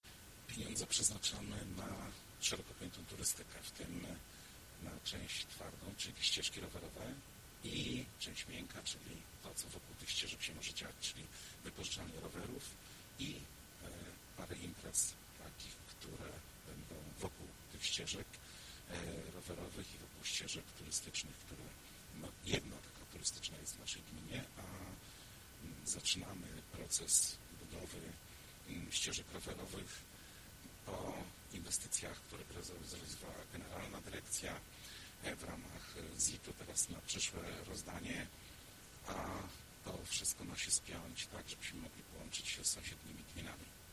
Wypowiedź: Wójt gminy Michałowice Krzysztof Grabka - ścieżki rowerowe